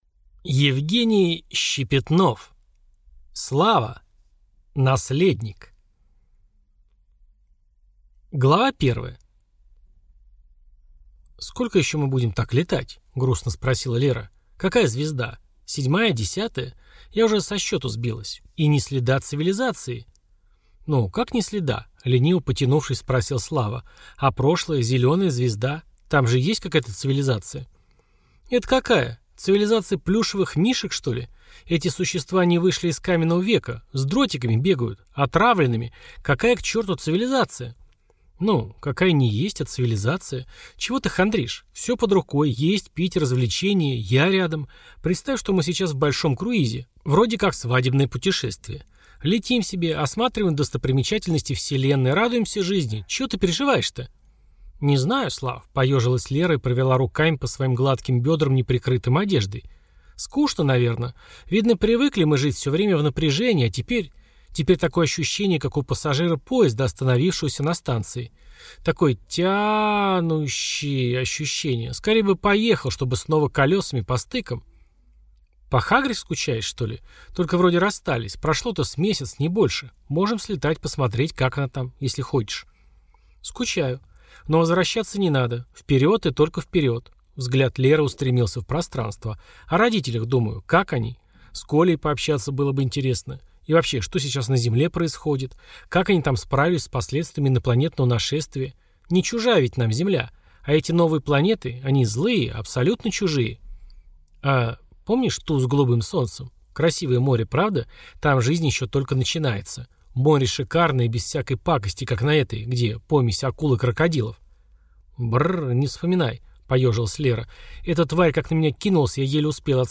Аудиокнига «Слава. Наследник» в интернет-магазине КнигоПоиск ✅ в аудиоформате ✅ Скачать Слава. Наследник в mp3 или слушать онлайн